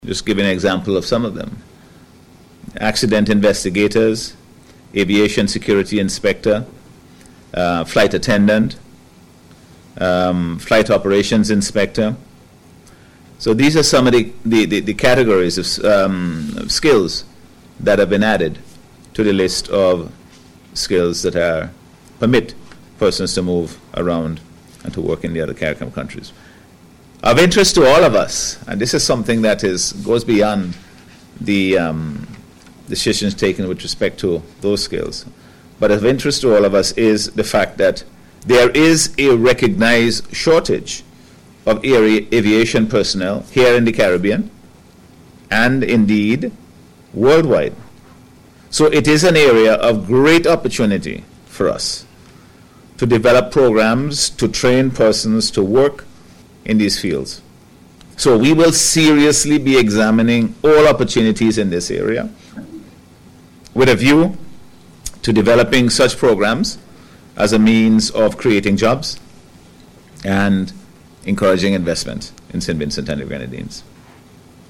Speaking at a News Conference at Cabinet Room on Tuesday, the Prime Minister said the programme now includes additional categories in the aviation sector.